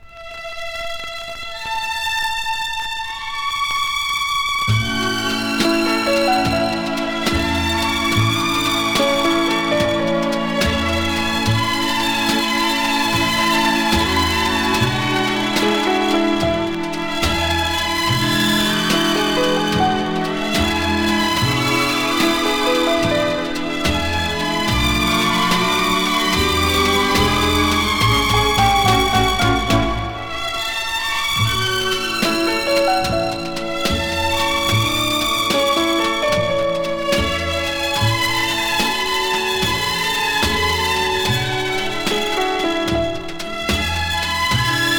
流麗で楽しさいっぱい、時折ドラマチックで楽しく爽やかな1枚。"
Pop, Easy Listening　UK　12inchレコード　33rpm　Stereo